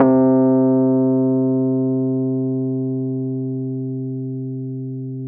RHODES-C2.wav